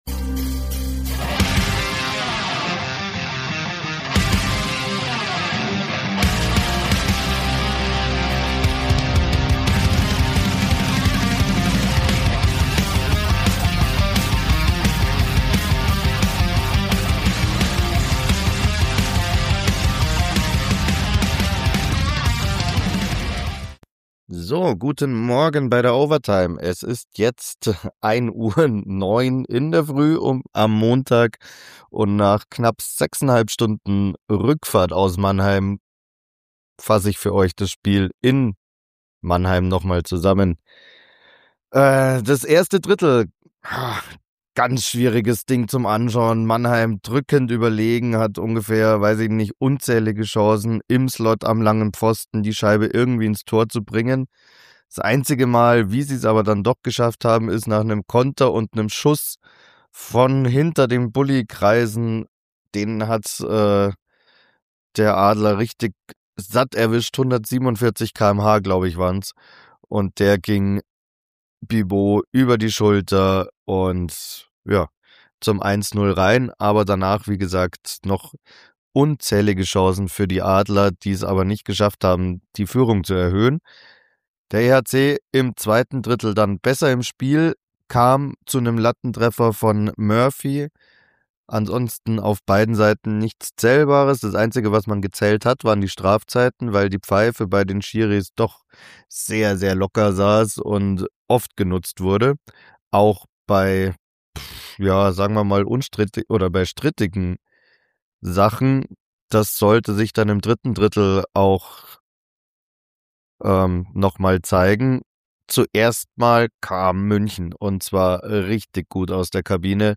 Nach 6 Stunden Heimfahrt im Schneesturm gibt es trotzdem noch eine Zusammenfassung des Spiels in Mannheim. Danach die Pressekonferenz und eine Premiere.